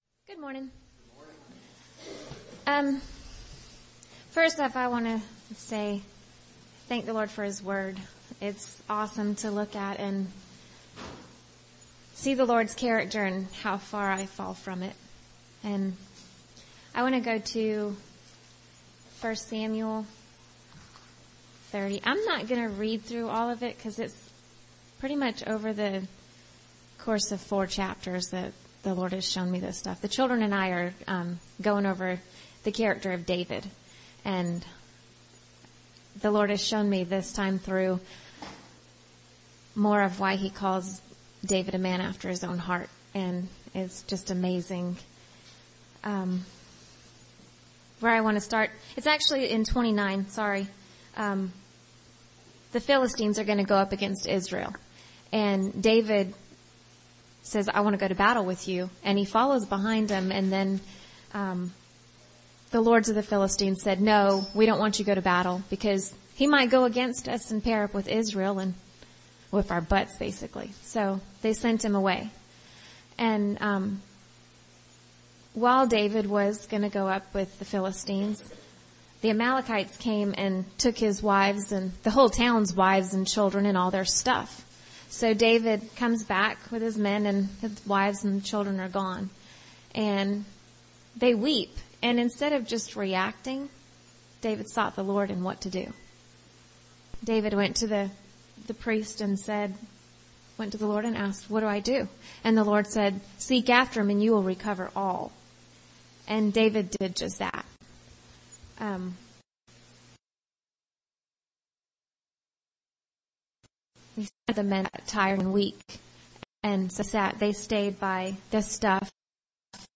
Sunday South Carolina Church Service 01/18/2015 | The Fishermen Ministry